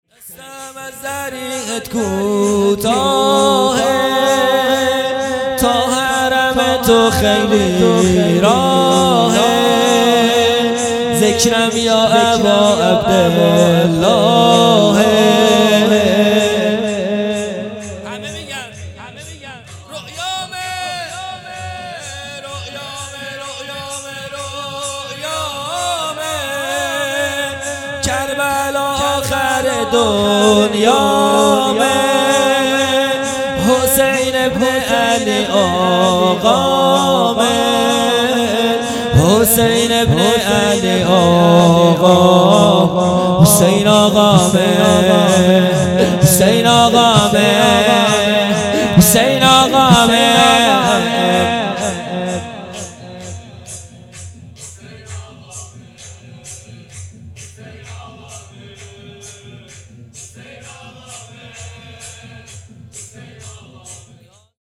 خیمه گاه - هیئت بچه های فاطمه (س) - شور | دستم از ضریحت کوتاهه
جلسۀ هفتگی | به مناسبت شهادت امام سجاد (ع)